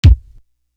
Movie Kick.wav